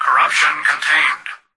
"Corruption contained" excerpt of the reversed speech found in the Halo 3 Terminals.
H3_tvox_fix3_corrptncontained_(unreversed).mp3